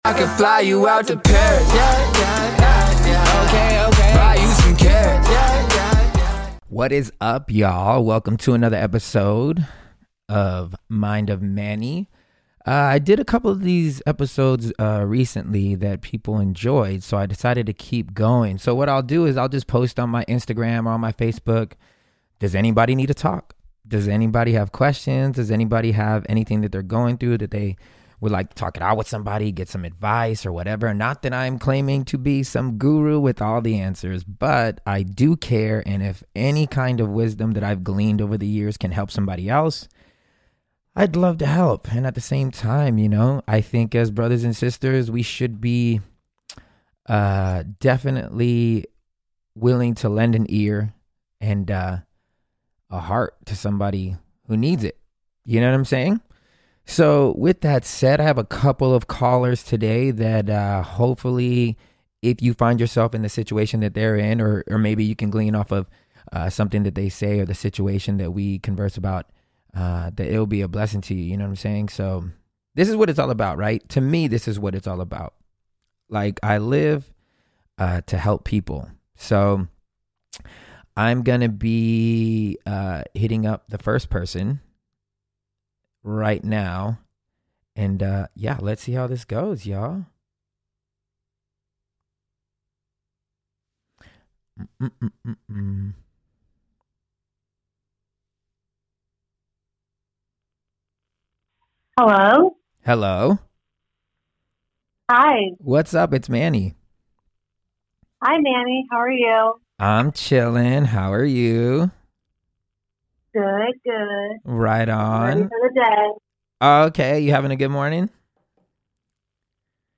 In this episode we talking about forgiveness after lying, expectations we put on the ones we love and feeling bad about succeeding when are family hasn't. Amazing calls from you brave souls that were willing to call in and share your stories.